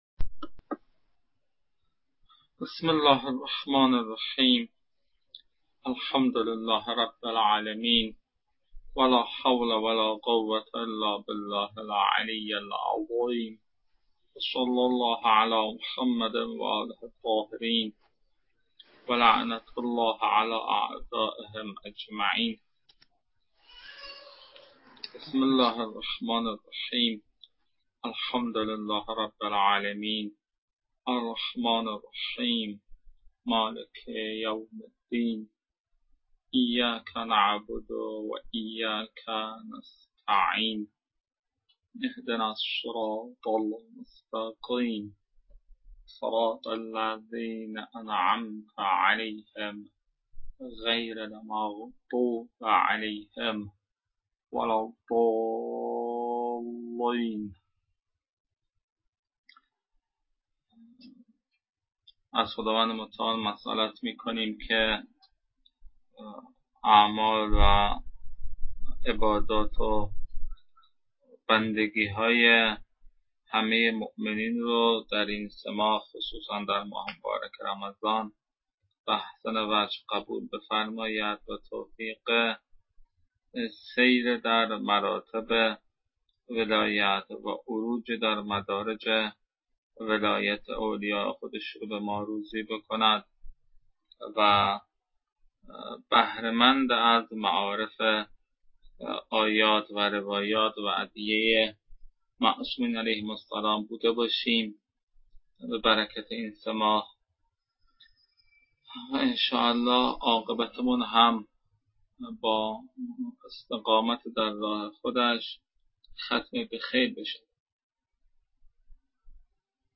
تدریس رساله اول